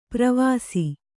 ♪ pravāsi